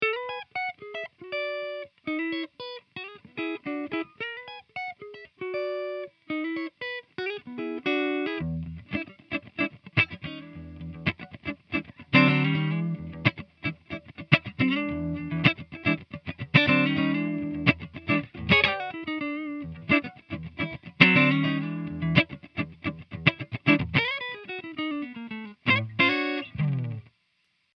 Clean riff 1